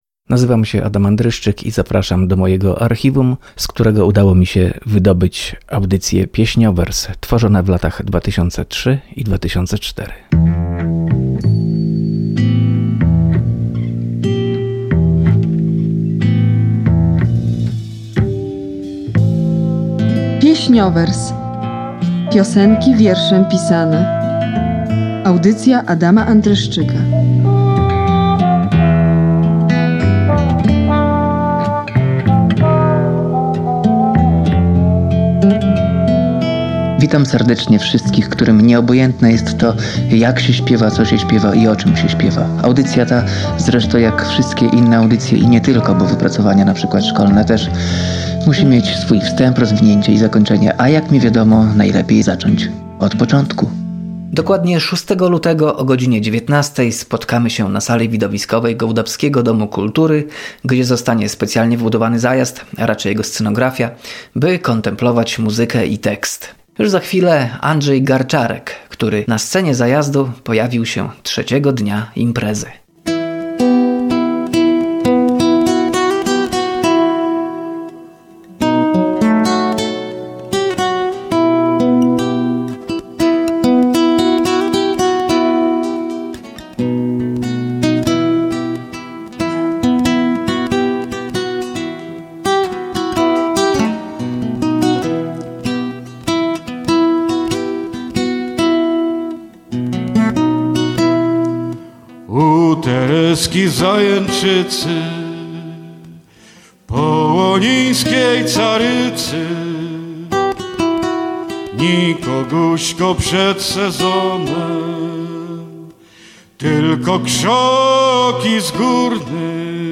Audycja poświęcona piosence literackiej, tworzona w latach 2003-2004 dla Radia Olsztyn. W latach 2021-2022 powtarzana w Radio Danielka.